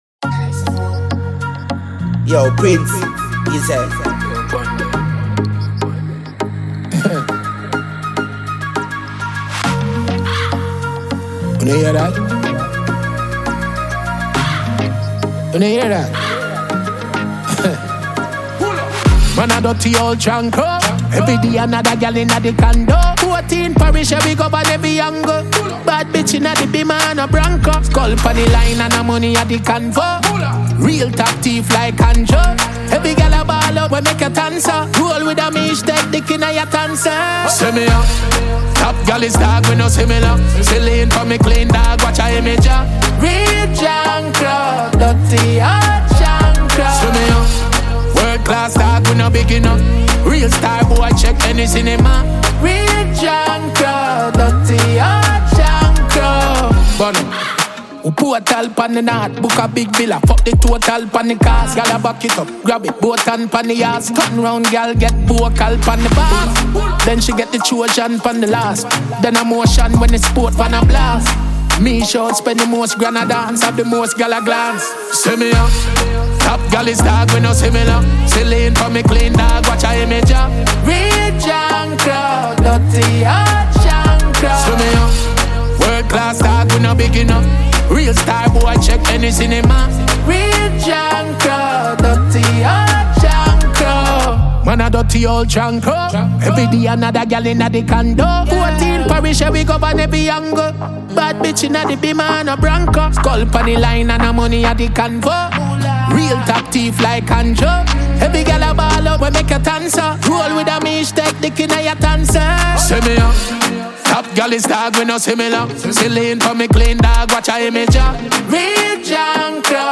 Dancehall
Jamaican dancehall artist